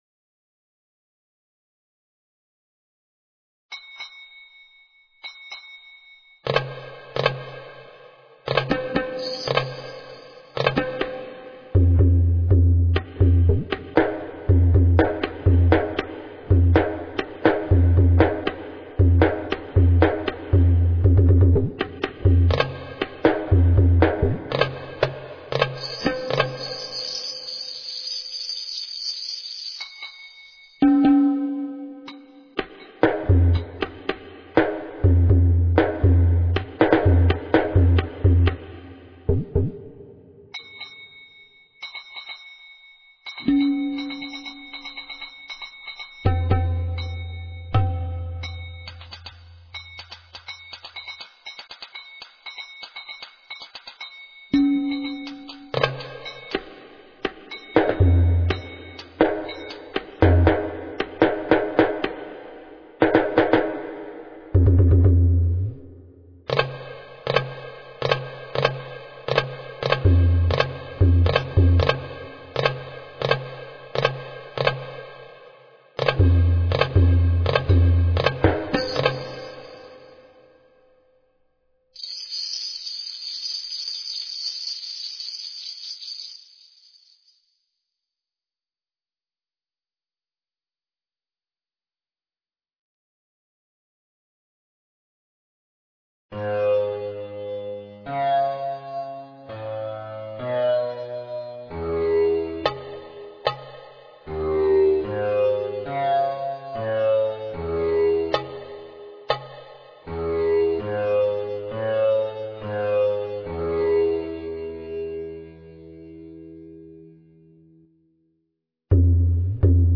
Royalty free middle eastern percussion ensemble.